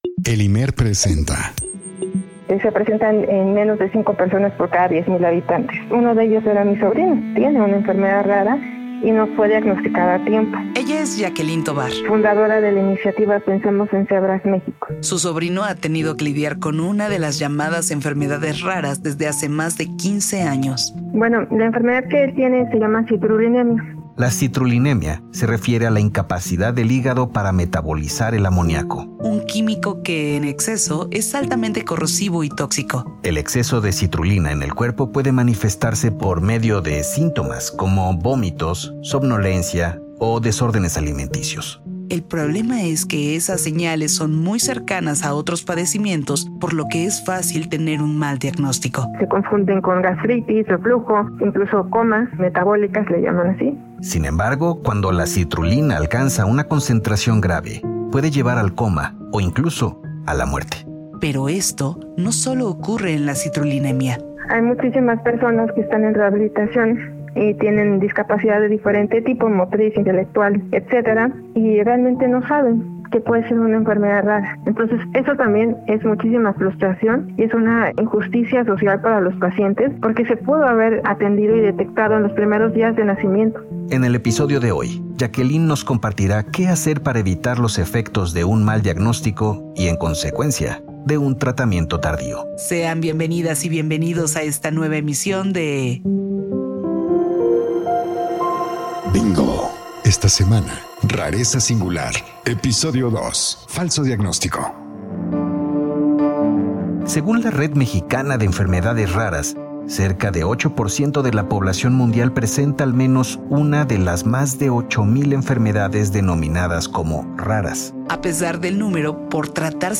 ENTREVISTAS: